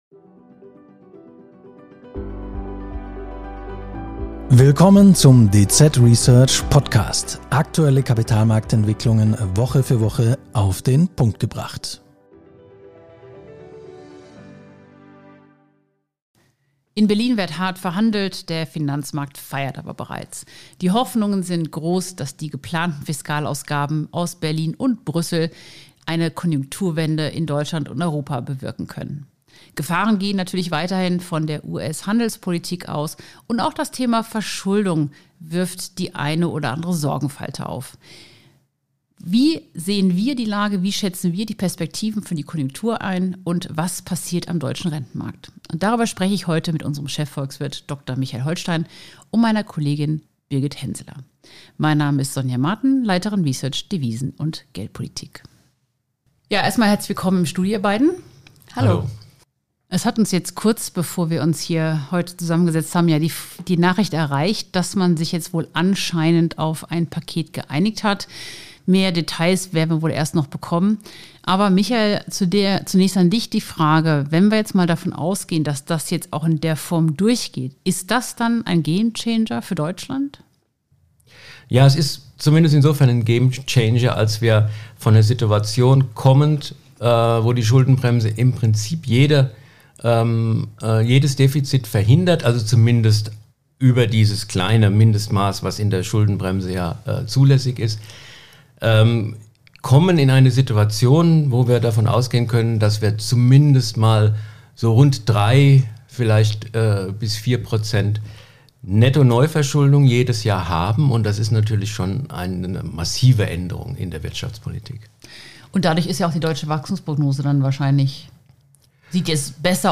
Heute im Gespräch